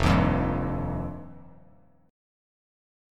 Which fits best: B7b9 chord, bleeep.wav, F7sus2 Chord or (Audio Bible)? F7sus2 Chord